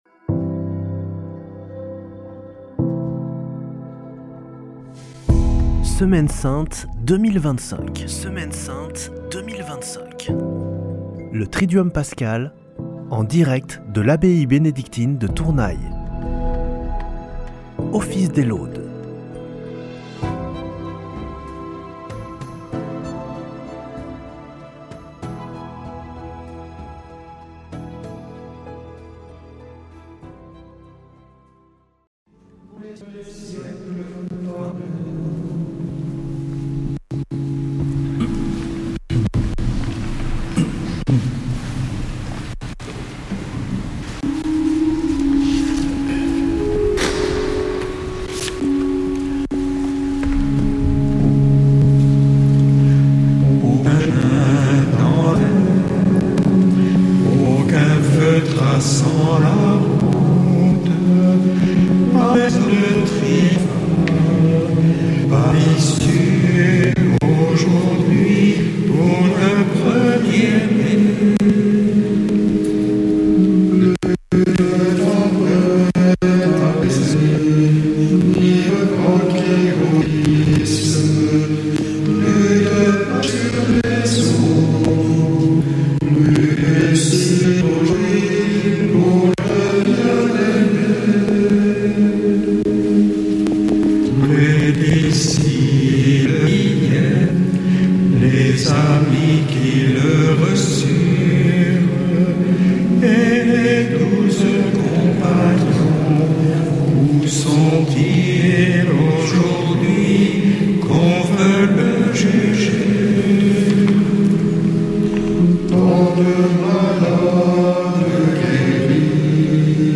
En direct, depuis l'abbaye bénédictine de Tournay.